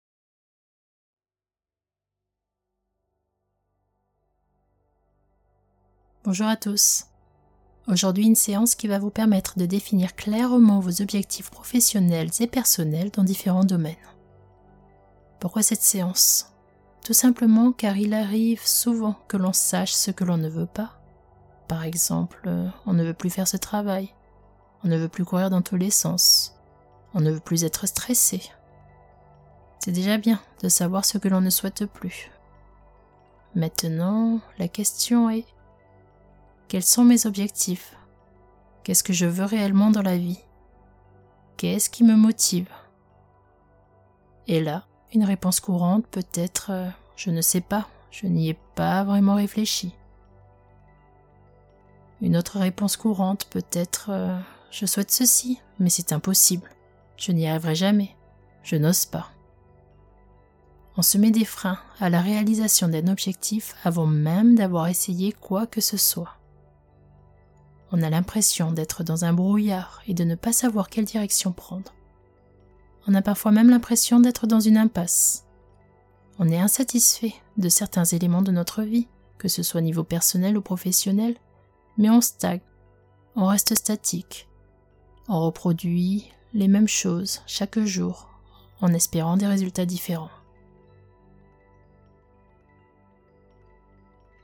extrait-Hypnose-pour-dormir-definir-et-atteindre-ses-objectifs.mp3